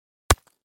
На этой странице собраны реалистичные звуки разрушения зданий: обвалы, взрывы, треск конструкций.
Удар кулаком по бетонной стене